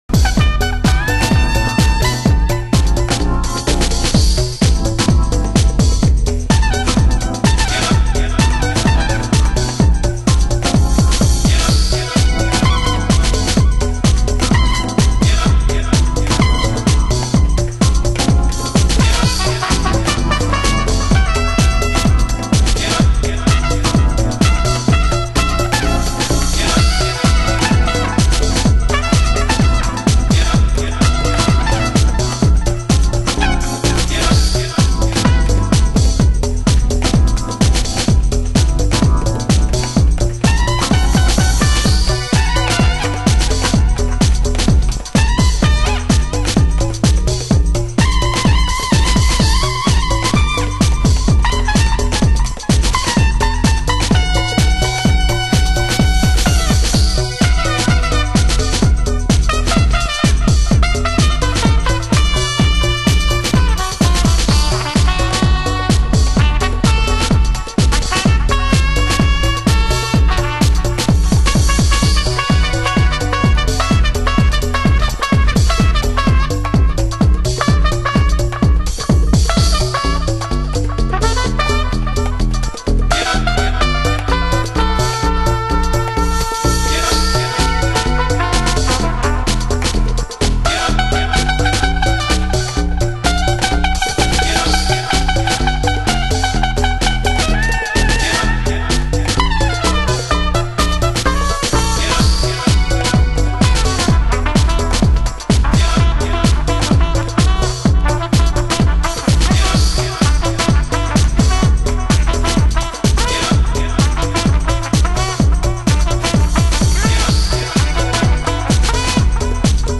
盤質：B面に軽い盤面汚れ（試聴箇所になっています）/少しチリパチノイズ有